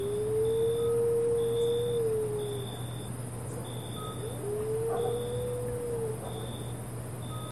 Gray Wolf - Canis lupus italicus.m4a